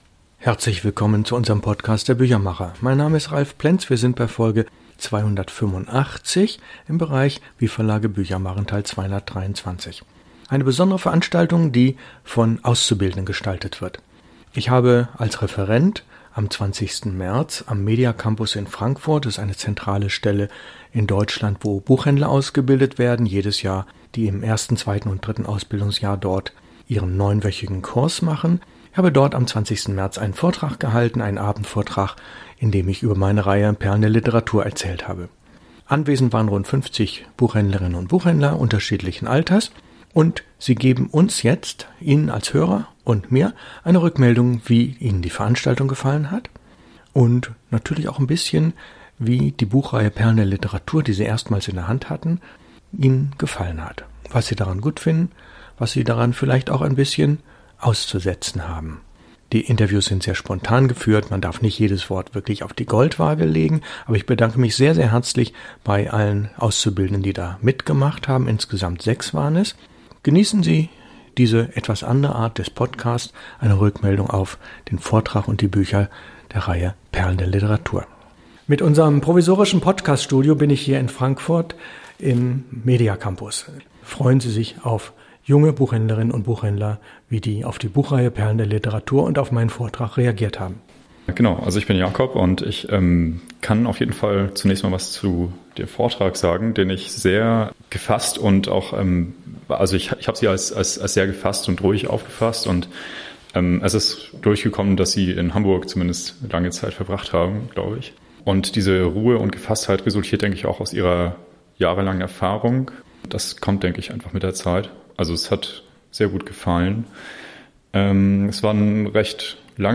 Auszubildende Buchhändler*innen am Mediacampus in Frankfurt am Main im Gespräch